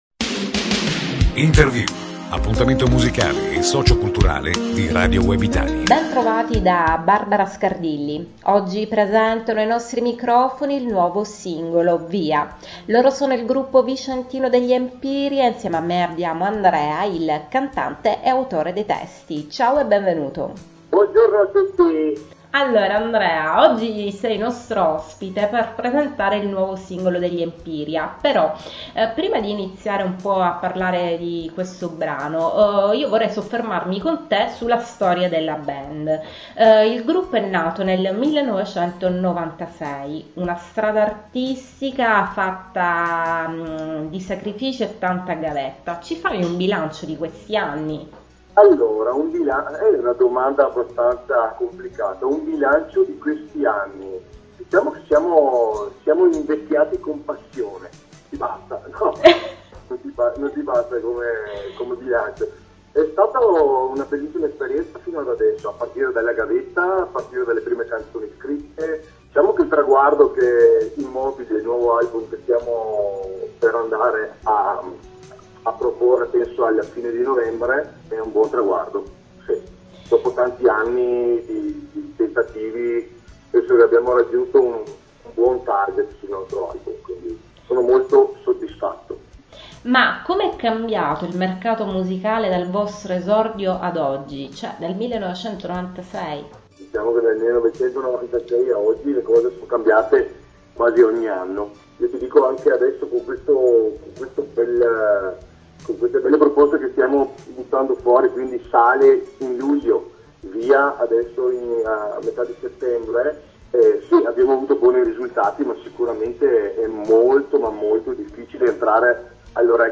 Intervista al gruppo vicentino degli Empiria - Radio Web Italia